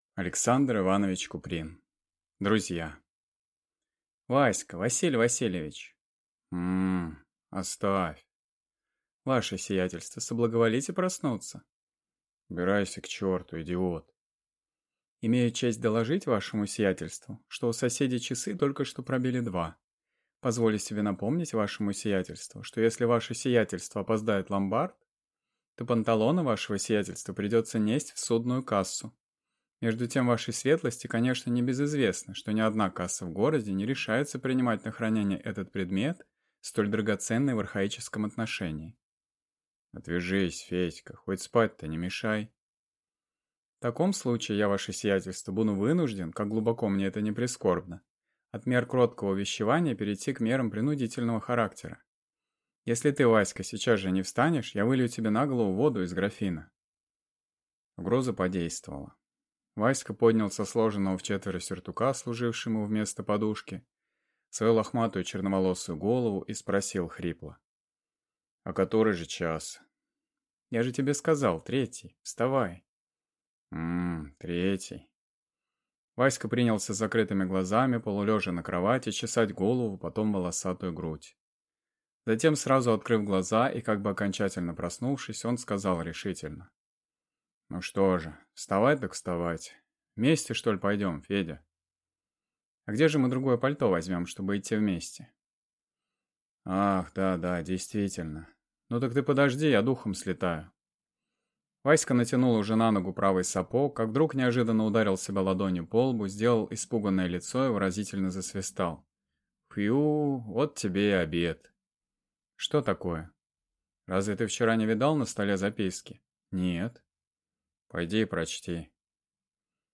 Аудиокнига Друзья | Библиотека аудиокниг